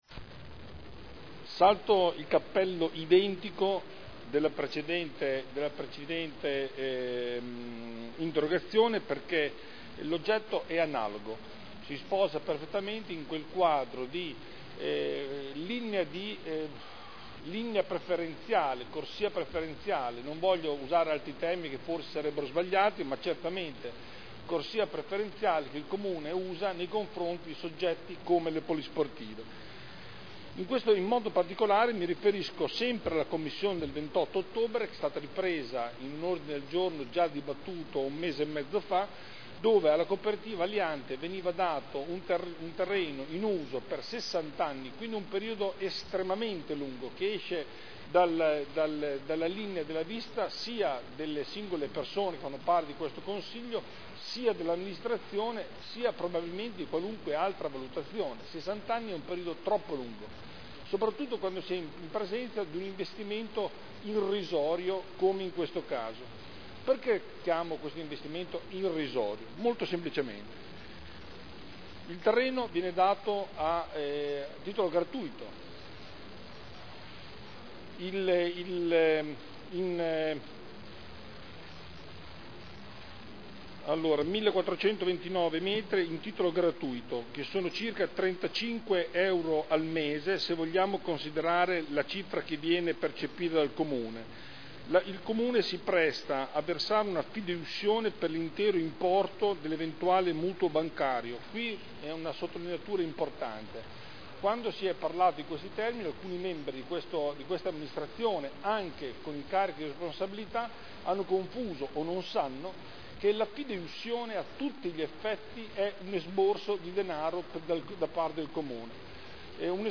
Seduta del 24/11/2011. Interrogazione dei consiglieri Galli, Pellacani, Taddei (PdL) sulla Coop. “Aliante” – Primo firmatario consigliere Galli (presentata il 4 novembre 2010 - in trattazione il 24.1.2011)